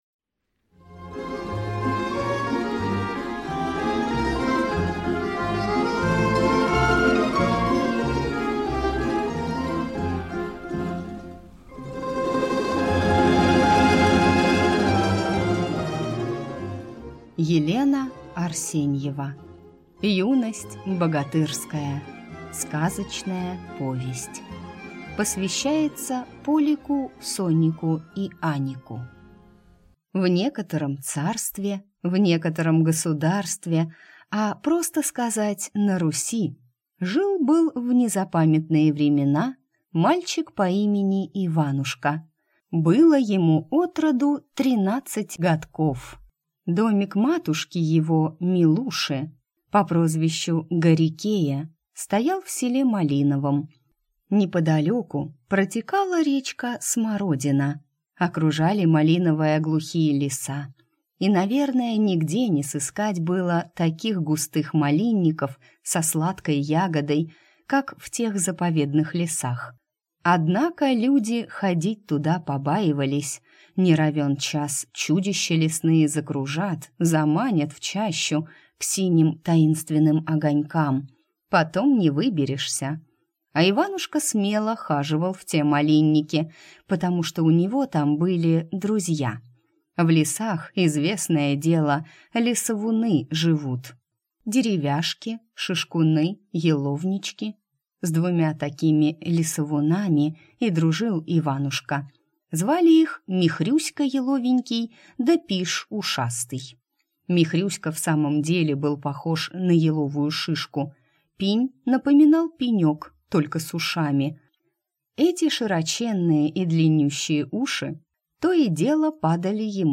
Аудиокнига Юность богатырская | Библиотека аудиокниг